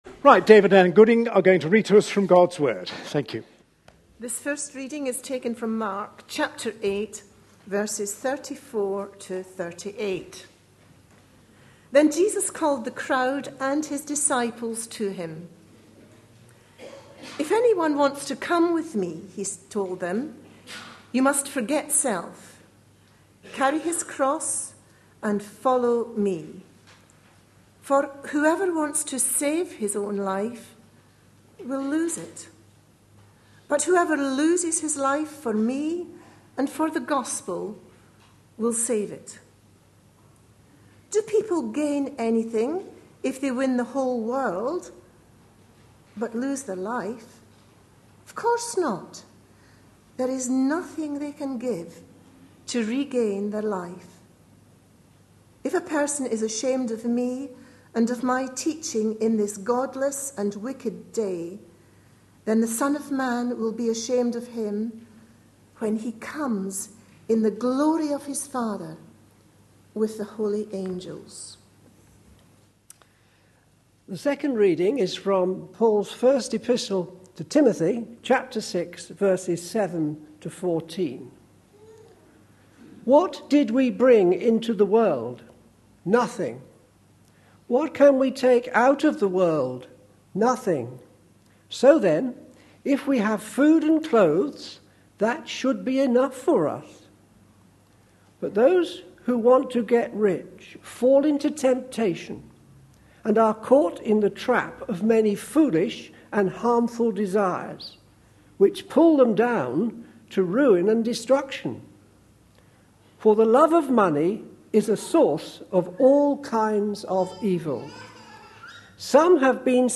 A sermon preached on 8th September, 2013.